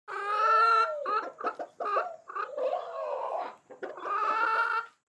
دانلود آهنگ مرغ 2 از افکت صوتی انسان و موجودات زنده
دانلود صدای مرغ 2 از ساعد نیوز با لینک مستقیم و کیفیت بالا
جلوه های صوتی